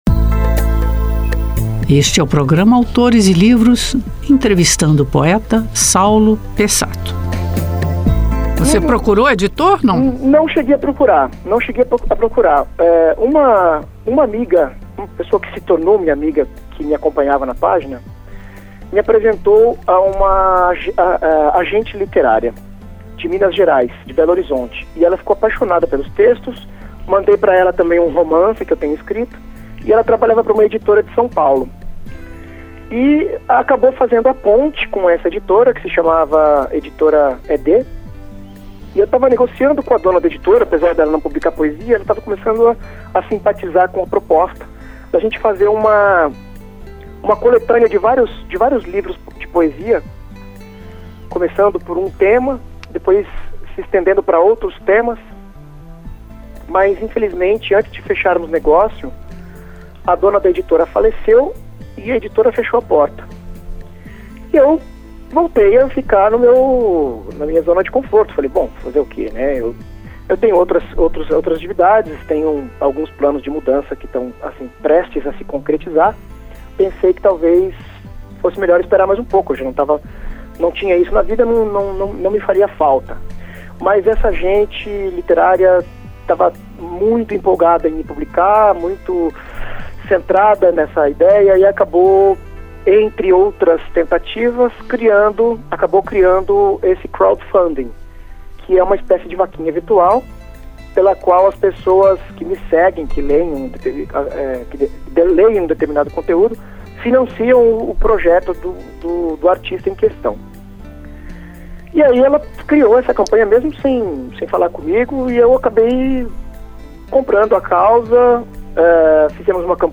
O entrevistado desta semana do programa Autores e Livros é o poeta, escritor e professor de literatura